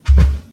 sounds / mob / cow / step1.ogg